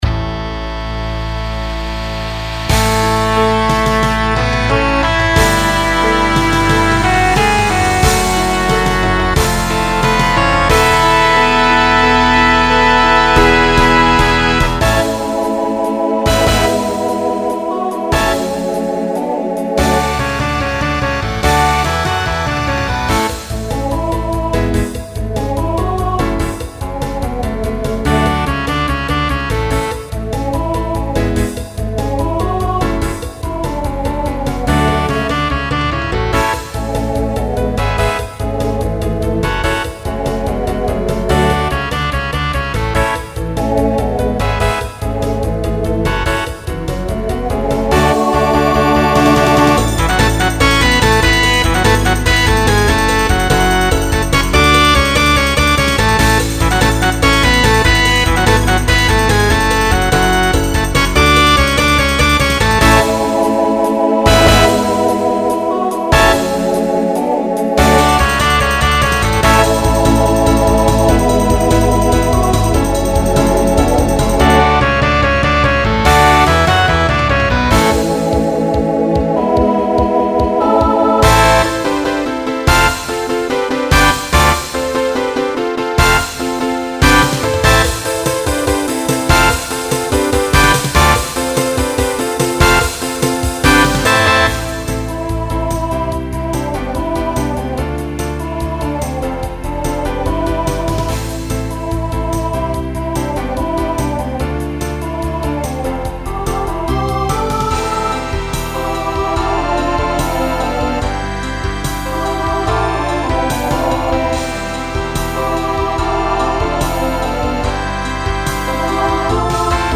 Genre Rock
Transition Voicing Mixed